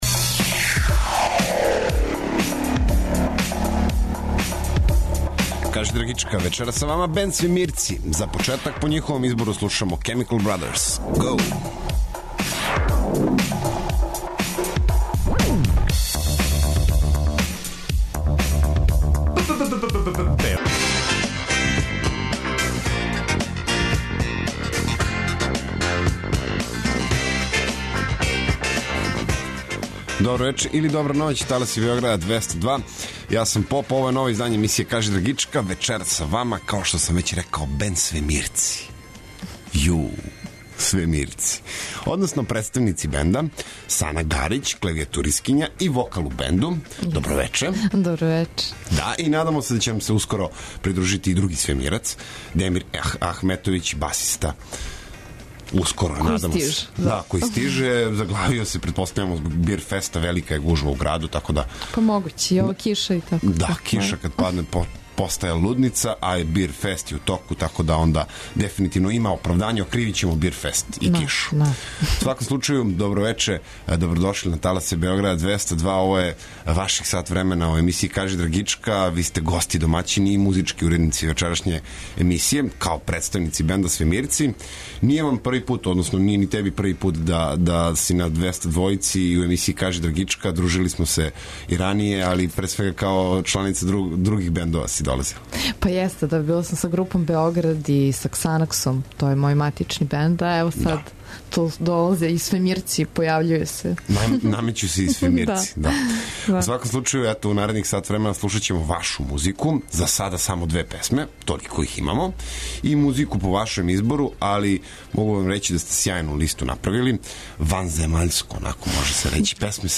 У новом издању емисије ''Кажи драгичка'' дружимо се са групом ''Свемирци'', који су на недавно завршеној 49. Зајечарској гитаријади освојили друго место.